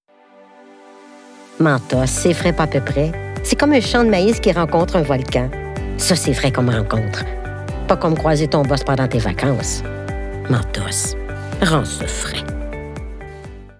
DÉMO(S) VOIX